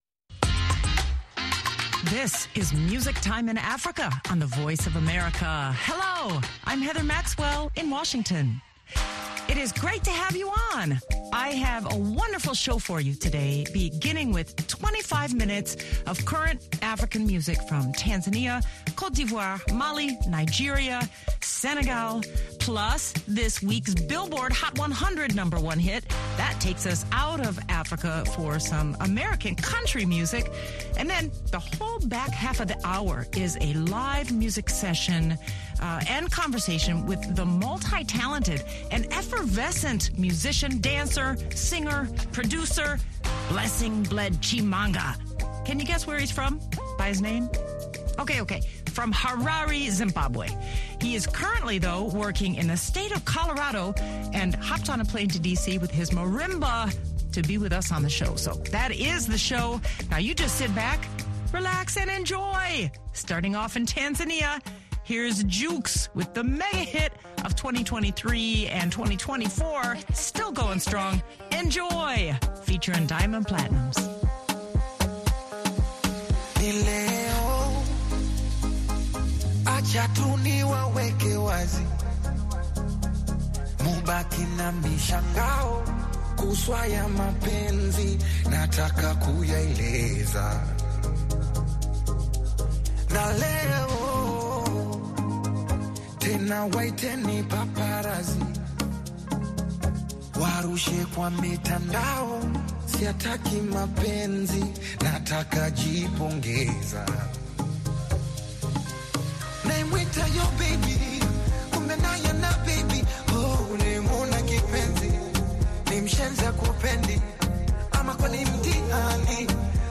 The feature at the back half is a live, in-studio performance by marimba virtuoso